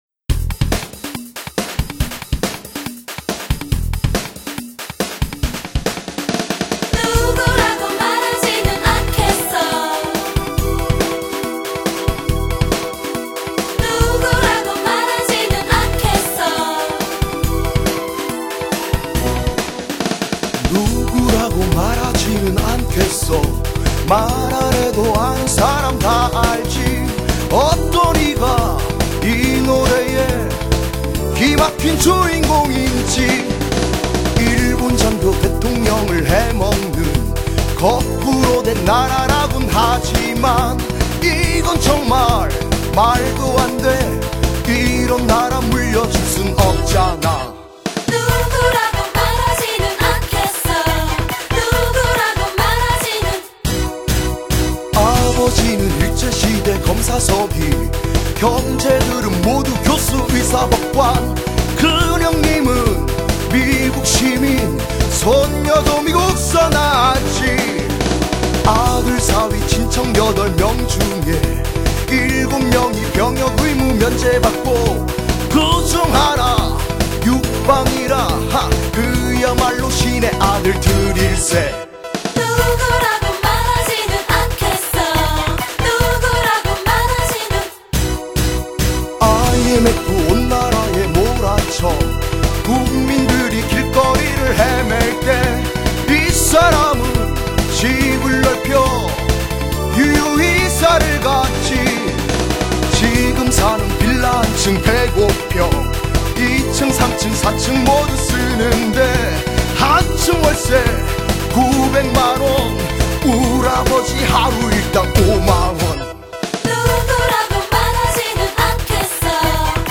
민중가요